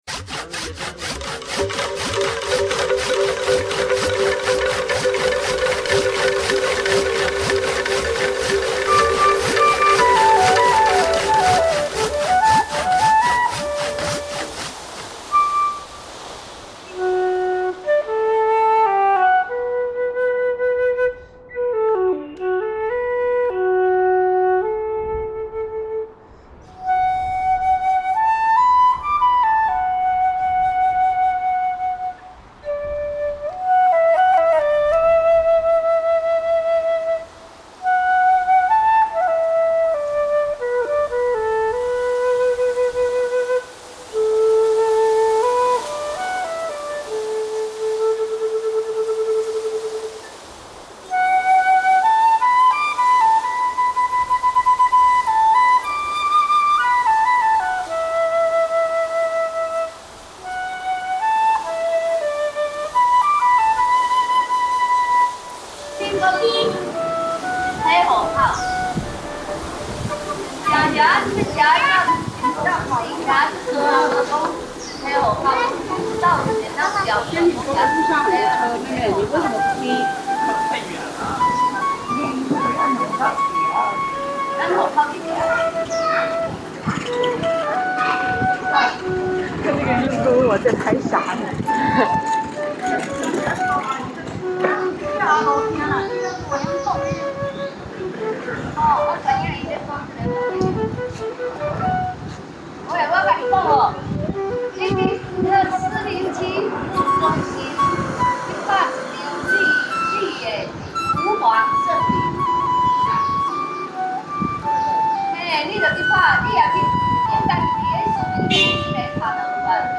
flute,bamboo, composition
Xiamen soundscape reimagined